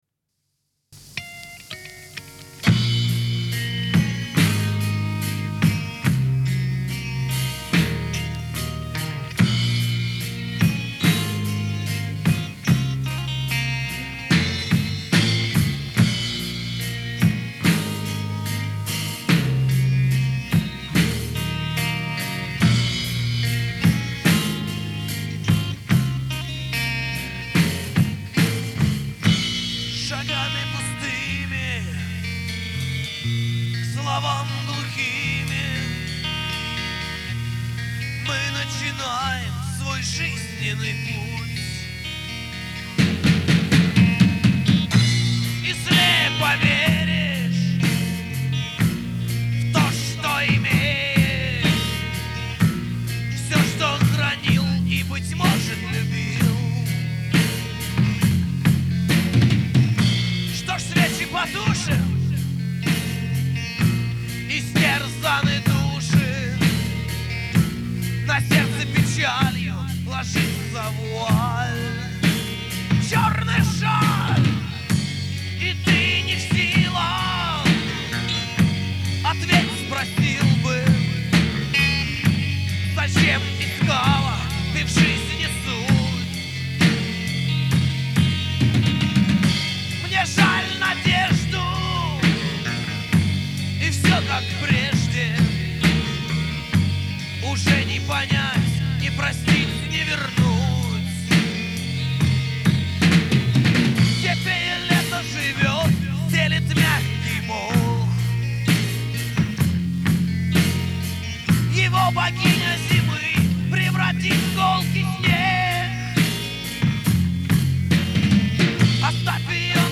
Играет в стилях: Thrash Metal